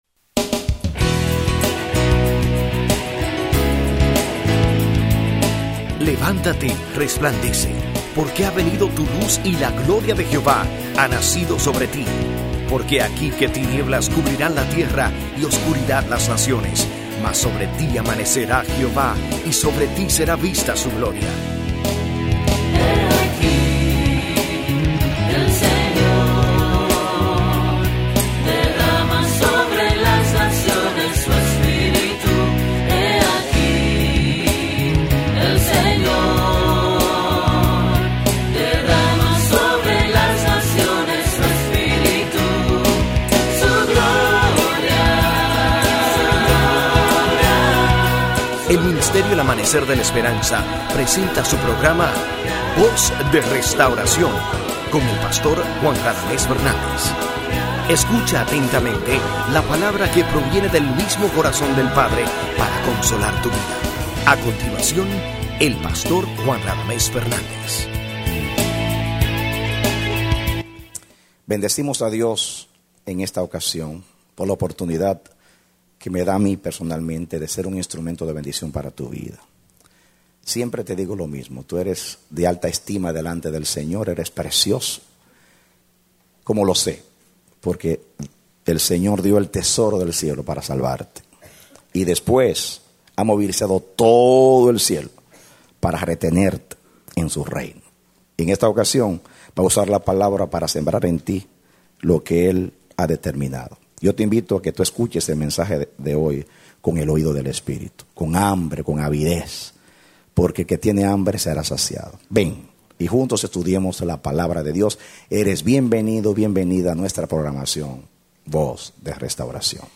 Serie de la Fe Predicado Mayo 8, 2008